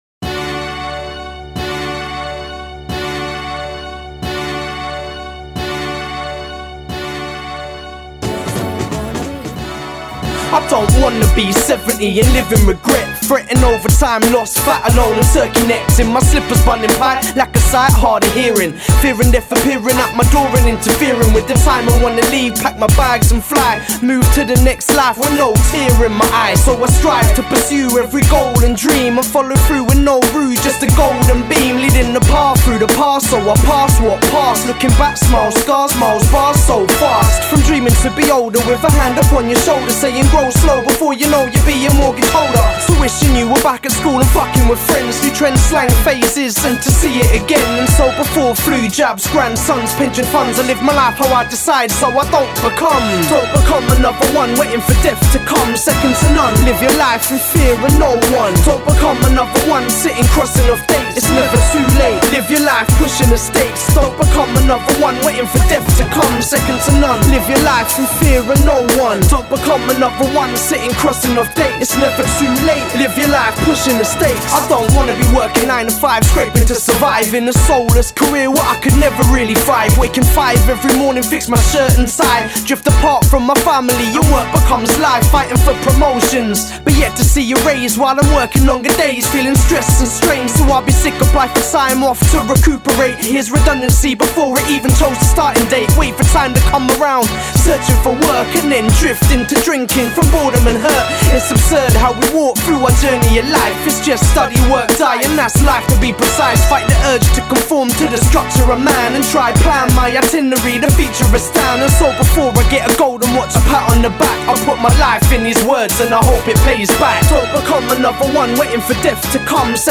Styl: Hip-Hop